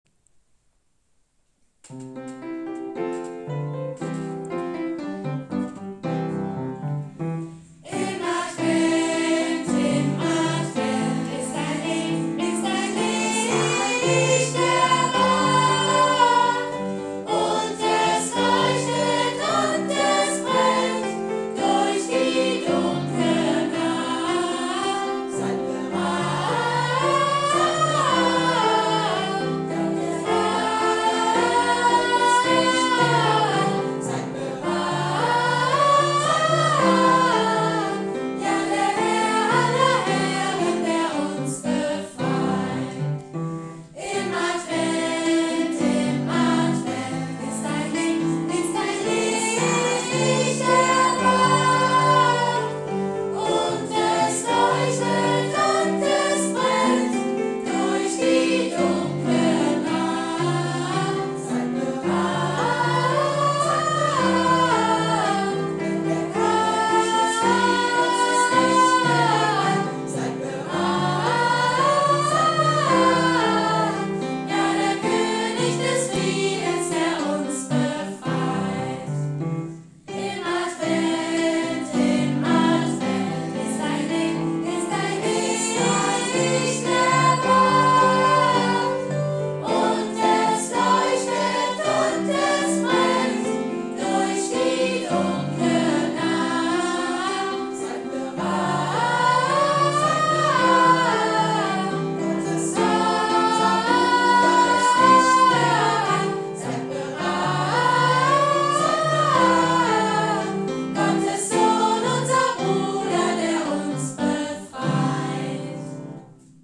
Kinder- und Jugendchor Lichtblick Kaldauen
Zum Anhören dieses erfrischenden Adventsliedes klicken Sie auf den roten Button.